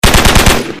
دانلود صدای تفنگ 2 از ساعد نیوز با لینک مستقیم و کیفیت بالا
جلوه های صوتی